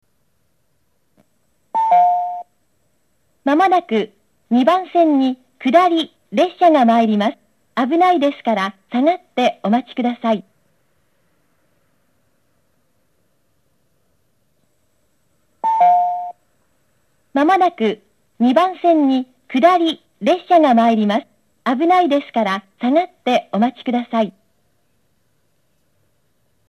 接近放送は1998~9年夏頃より永楽電気製の接近放送を使用しておりました。
接近放送
女性による接近放送です。